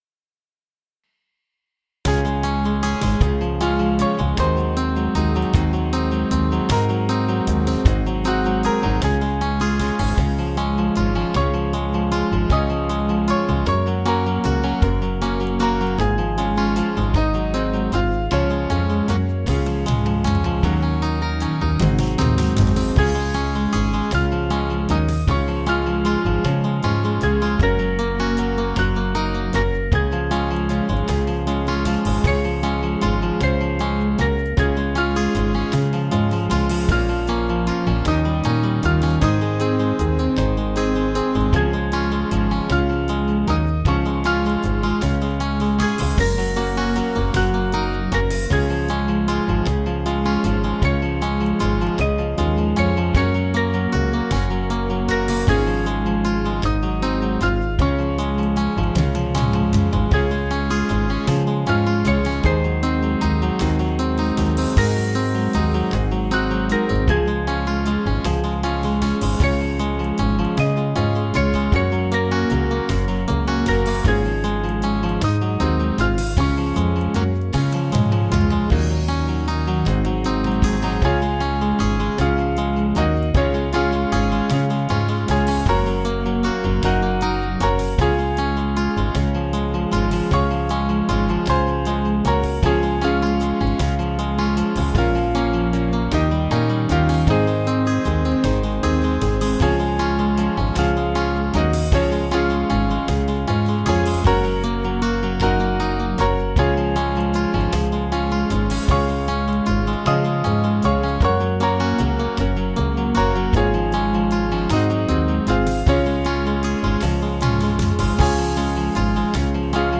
(CM)   3/Db 470.5kb
Small Band